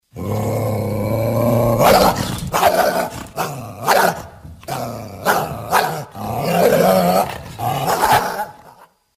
Рычание агрессивного койота